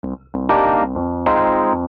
爵士乐罗德斯2
这个循环是F大调，速度为93 bpm。
Tag: 93 bpm Jazz Loops Piano Loops 1.74 MB wav Key : F